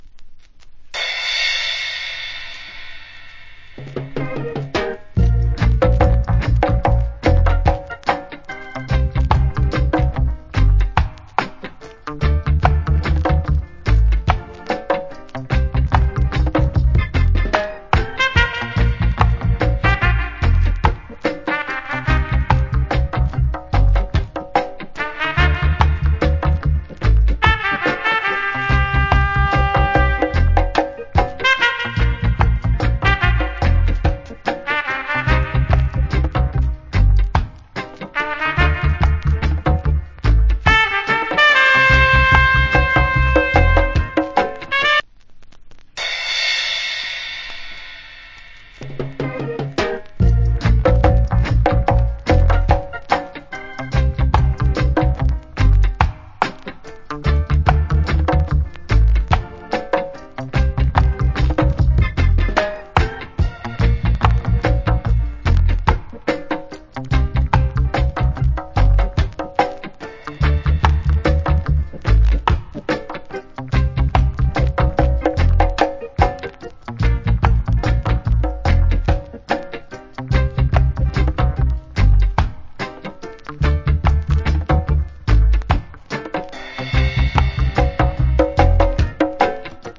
Wicked Horns Reggae Inst.